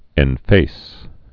(ĕn-fās)